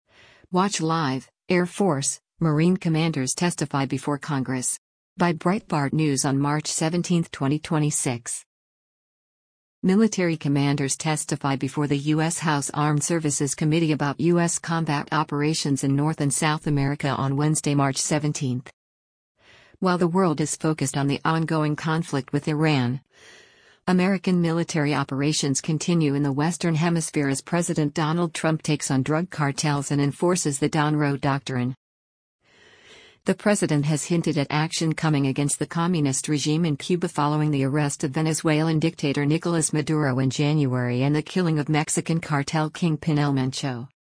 Military commanders testify before the U.S. House Armed Services Committee about U.S. combat operations in North and South America on Wednesday, March 17.